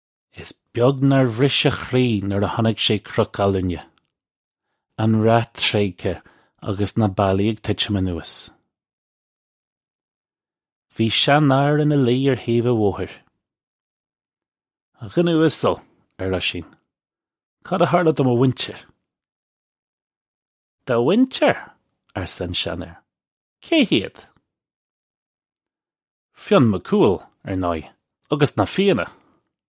Listen to the pronunciation here. This comes straight from our Bitesize Irish online course of Bitesize lessons.